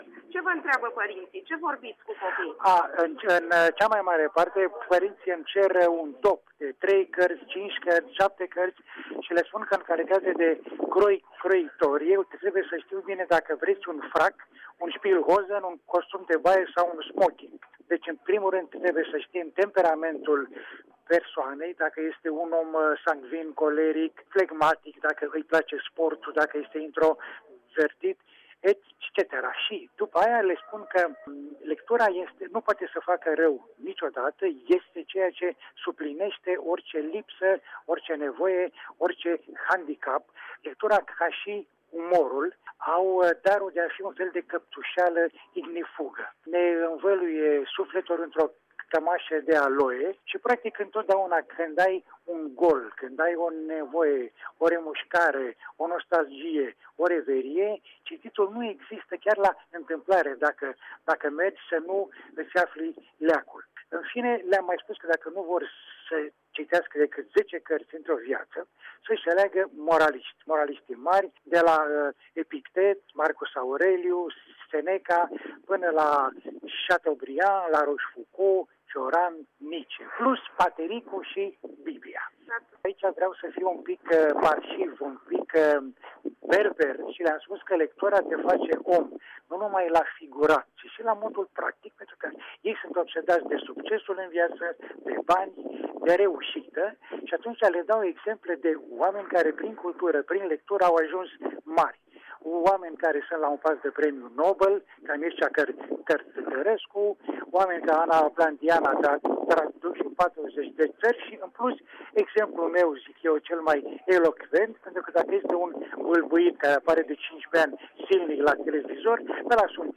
AUDIO: Interviu cu scriitorul Dan C. Mihăilescu